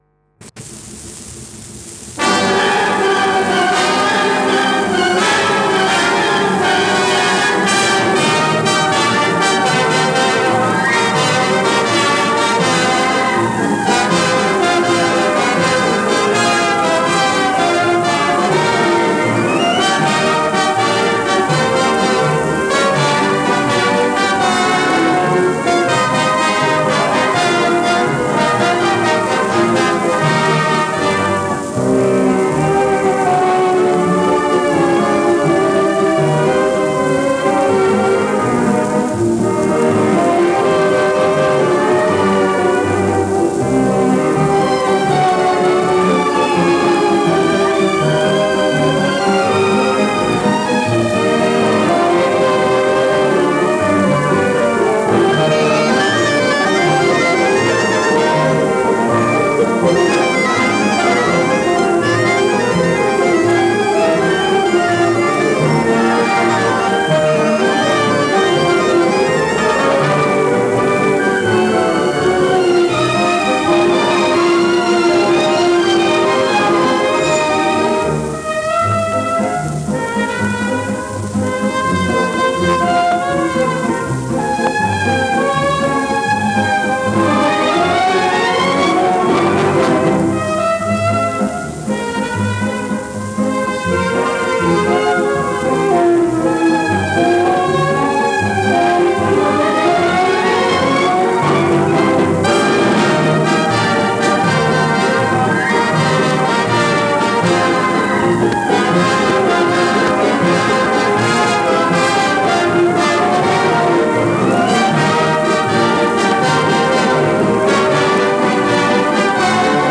Сегодня получил пластинку на 78 оборотов с этим вальсом.